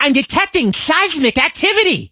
carl_start_vo_02.ogg